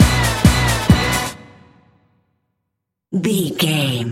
Epic / Action
Fast paced
Ionian/Major
synthesiser
drum machine
80s